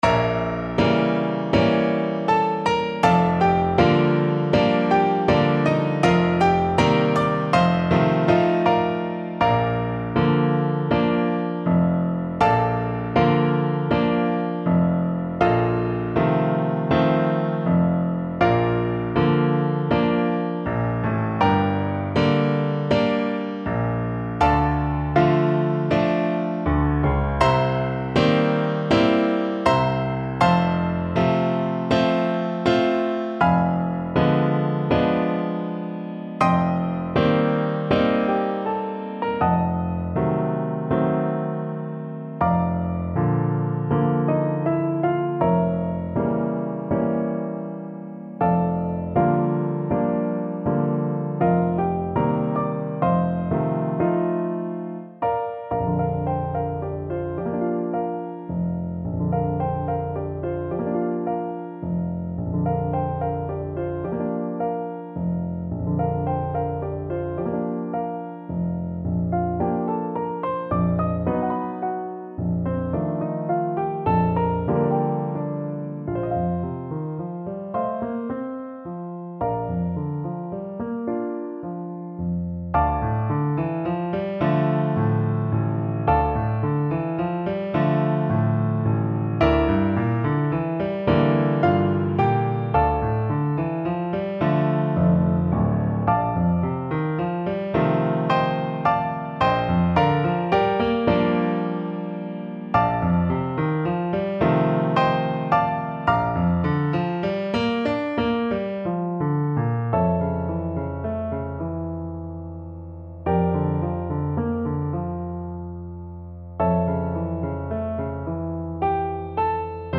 4/4 (View more 4/4 Music)
~ = 80 Allegretto moderato, ma non troppo
Classical (View more Classical Trumpet Music)